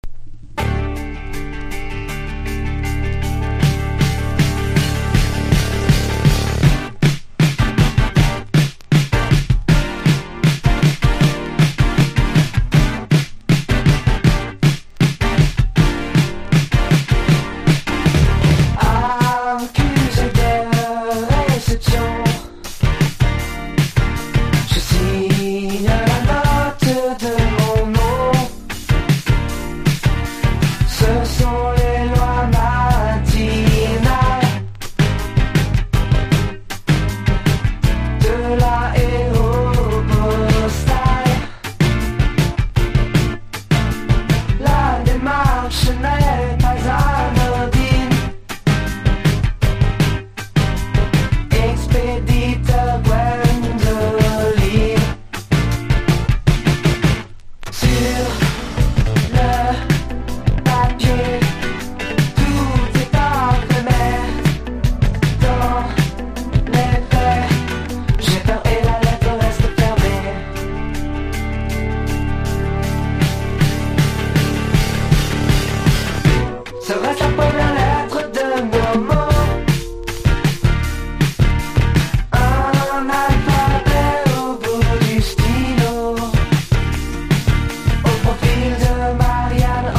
INDIE DANCE# NEW WAVE
フレンチ・シンセ・ロック・デュオ
メロディアスなヴォーカルと80'sを思わせるエフェクティブなギターで軽快に歌う好ナンバー。
全体的に80年代臭が凄いです。